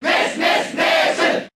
File usage The following 2 pages use this file: List of crowd cheers (SSBB)/Japanese Ness (SSBB) Transcode status Update transcode status No transcoding required.
Ness_Cheer_Japanese_SSBB.ogg